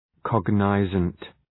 Προφορά
{‘kɒgnəzənt}